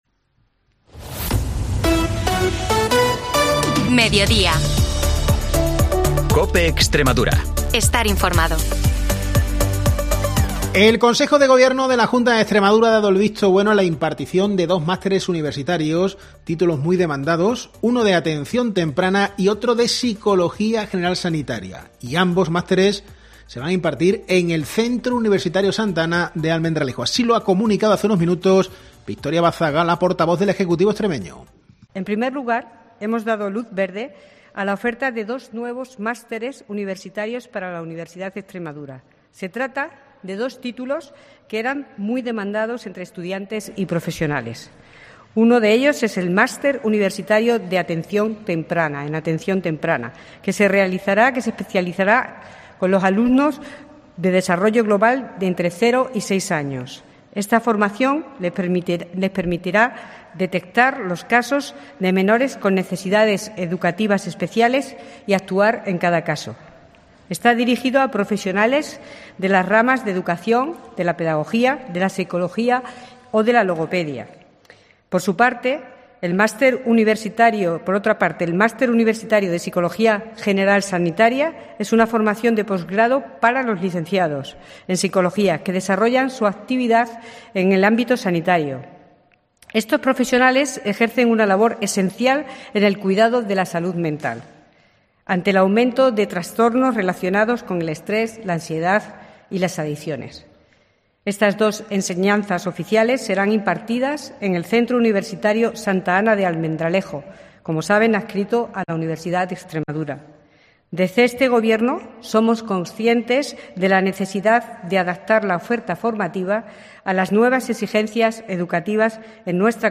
Información y entrevistas